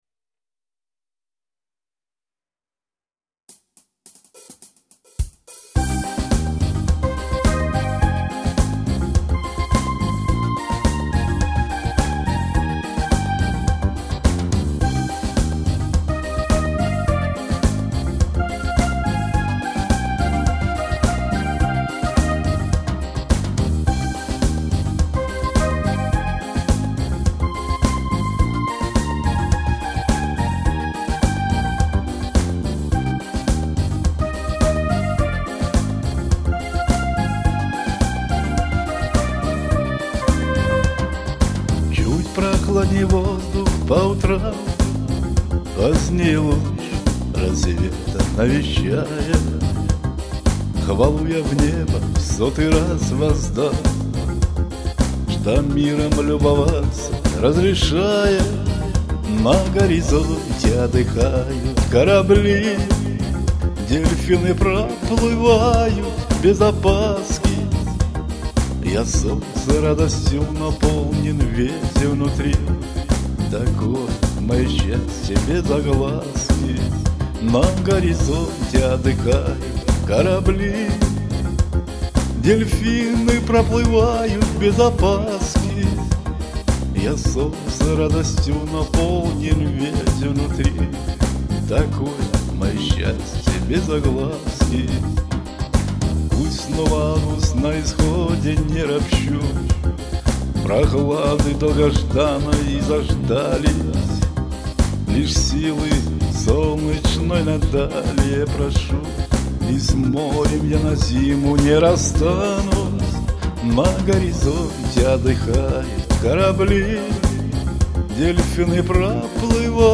Рубрика: Поезія, Авторська пісня
Самим понравилось...Я и не ожидала. что так споется душевно... 16 23